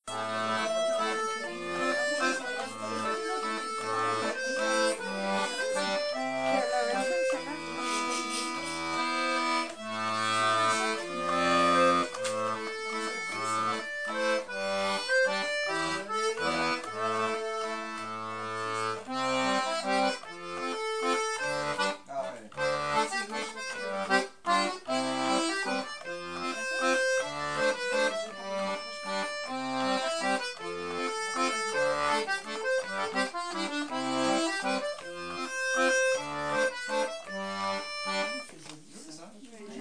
l'atelier d'accordéon diatonique
enregistrement simple et avec des variations d'accompagnement